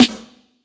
Snare MadFlavor 4.wav